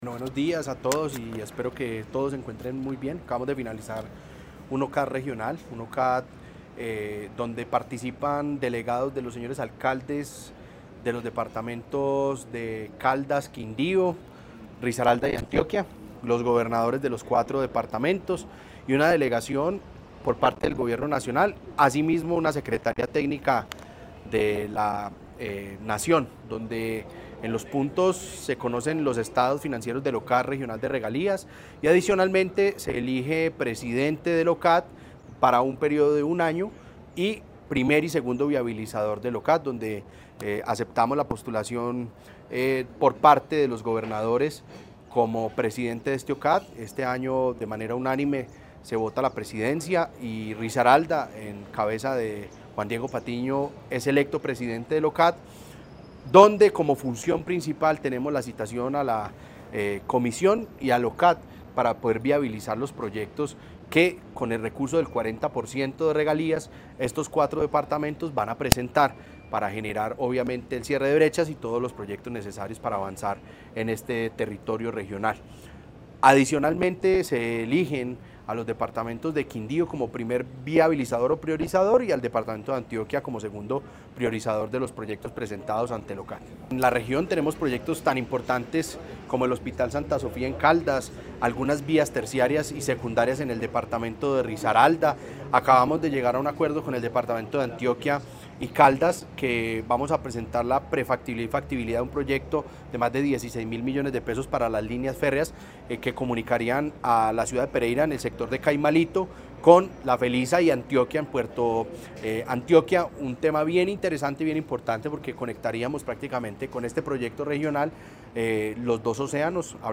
Juan Diego Patiño Ochoa, gobernador de Risaralda.